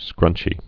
(skrŭnchē)